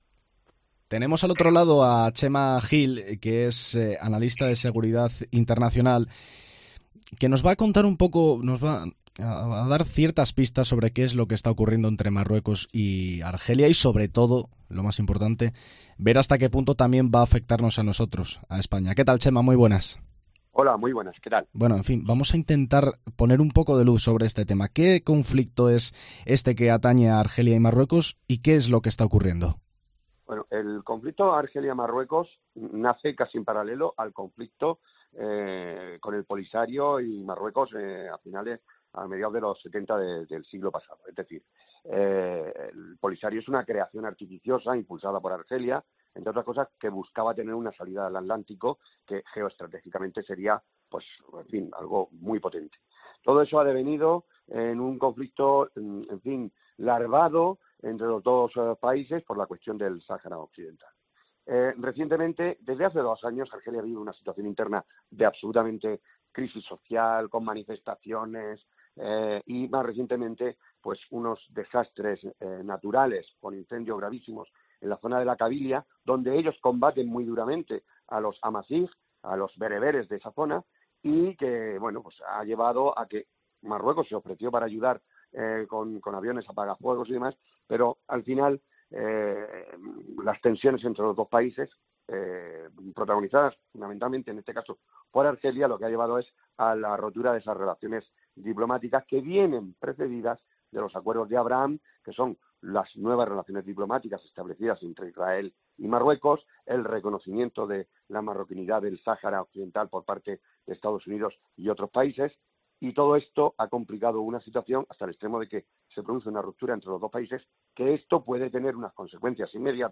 analista de Seguridad Internacional